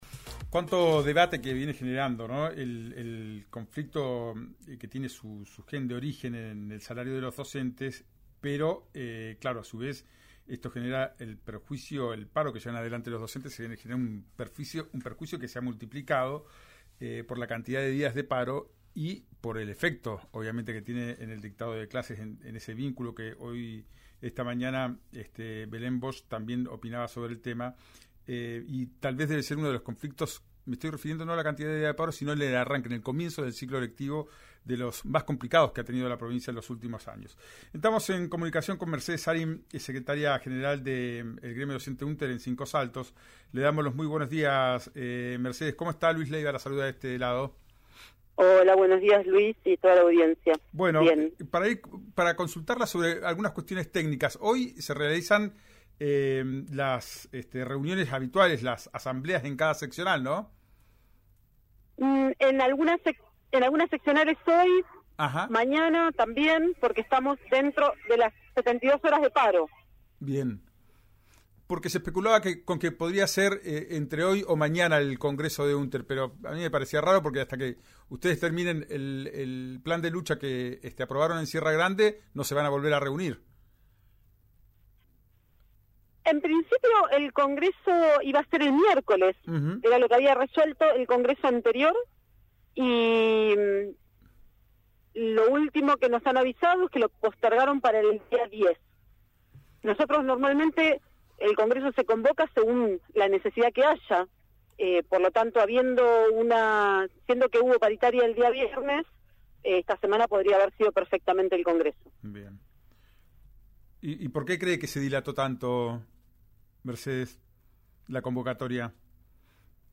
en RÍO NEGRO RADIO: